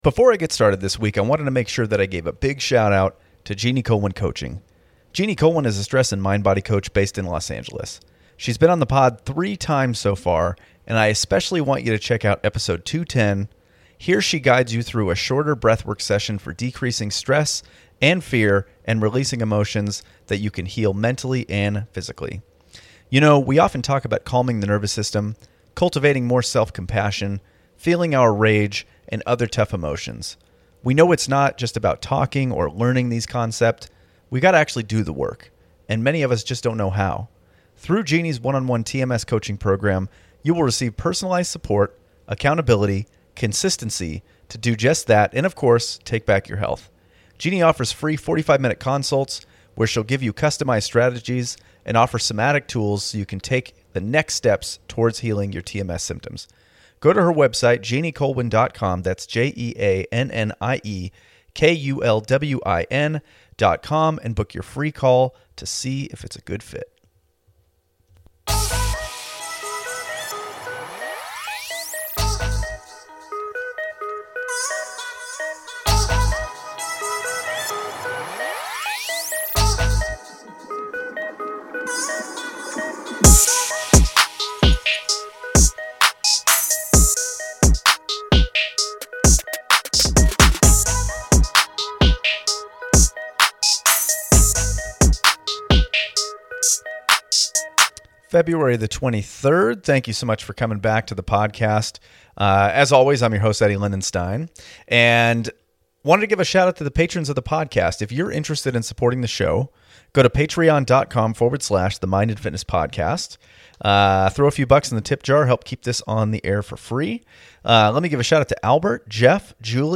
This week we dive into Part II of our 65 minute conversation.